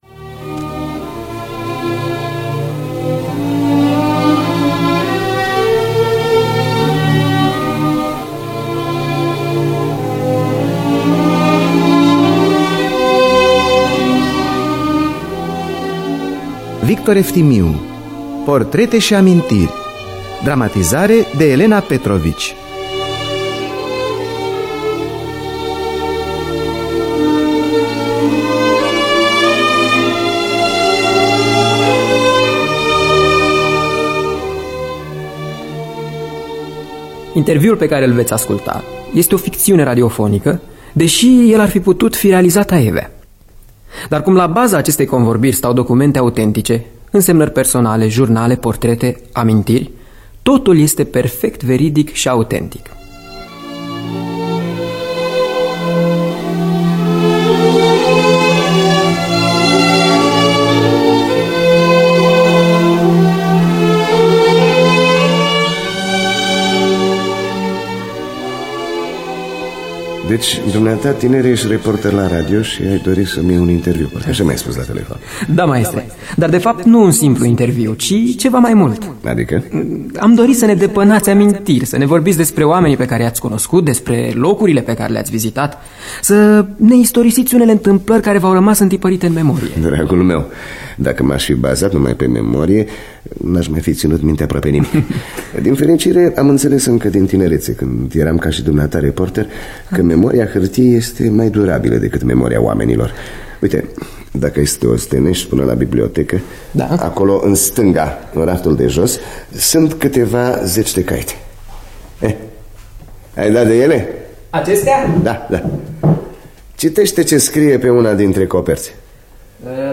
Scenariu radiofonic de Elena Petrovici.